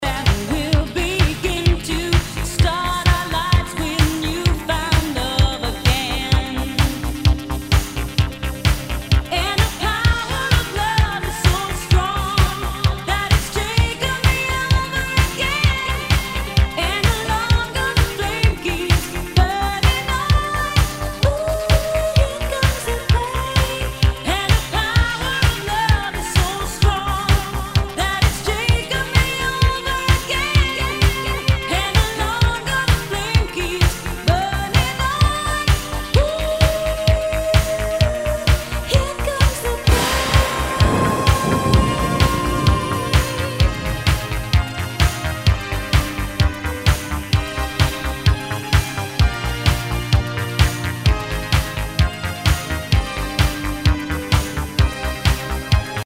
SOUL/FUNK/DISCO
ナイス！ハイエナジー / シンセ・ポップ・ディスコ！
少し盤に歪みあり